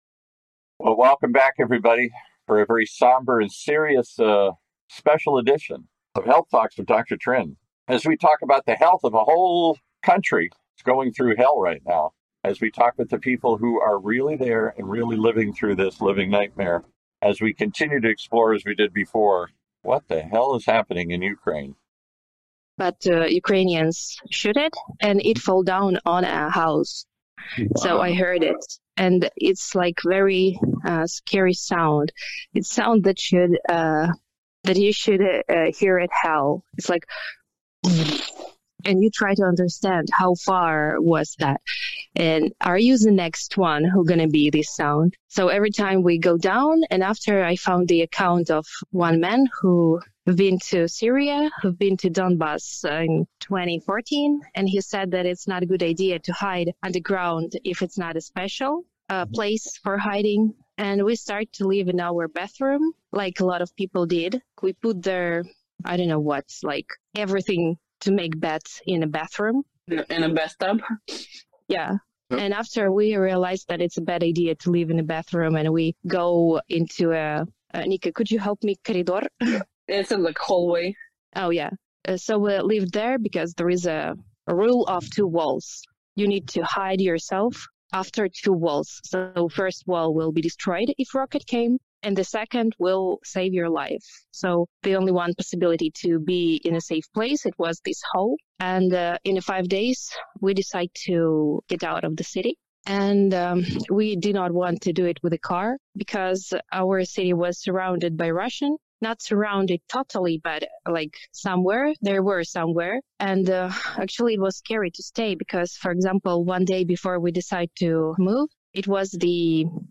Today is a rare opportunity to hear four brave women telling stories of what they’re seeing and experiencing in Ukraine.